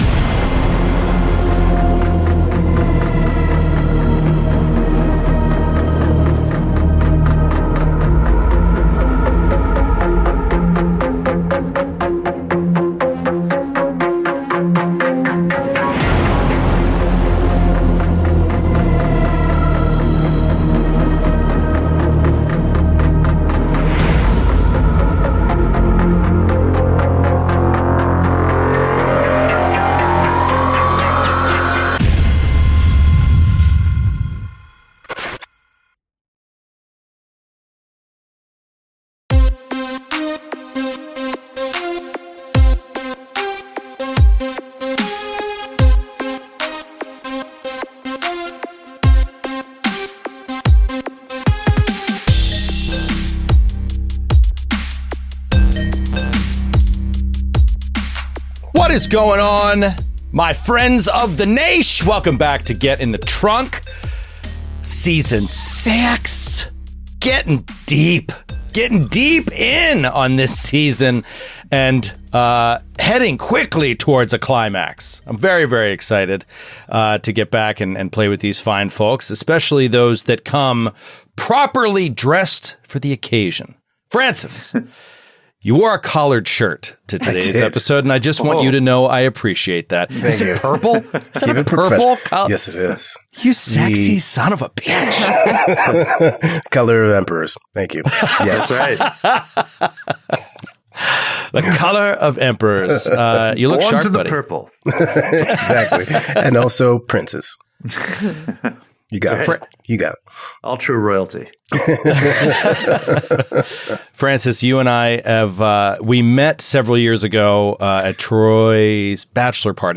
Get in the Trunk is an anthology series playing through various Delta Green scenarios by Arc Dream Publishing, such as Last Things Last, The Last Equation, A Victim of the Art and Ex Oblivione.